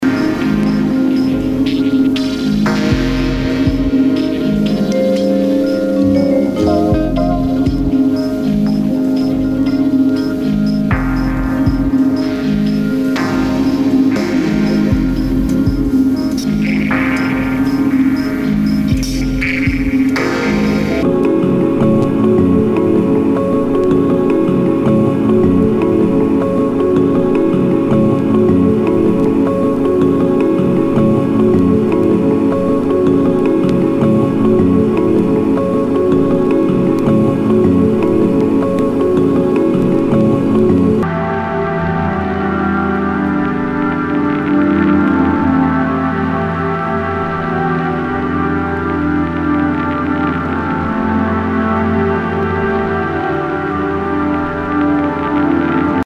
HOUSE/TECHNO/ELECTRO
ナイス！アンビエント！！